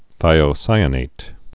(thīō-sīə-nāt)